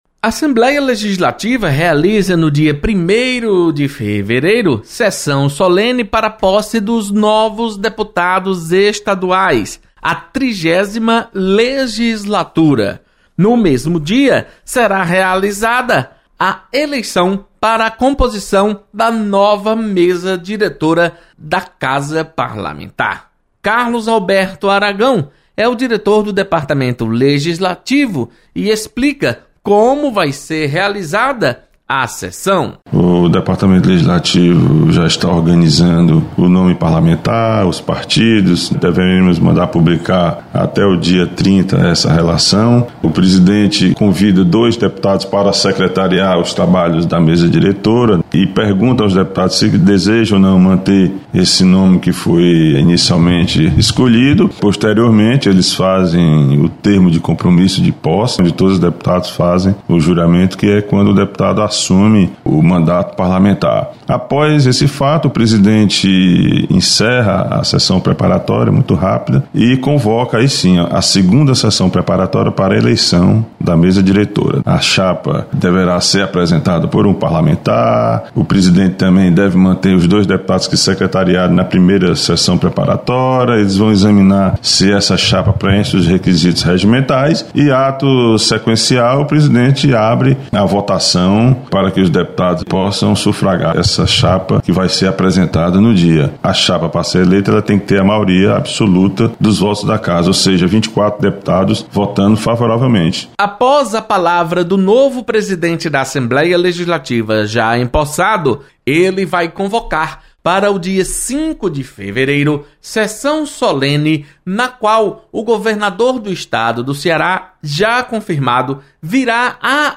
Posse dos deputados eleitos para 30ª Legislatura acontece dia primeiro de fevereiro. Repórter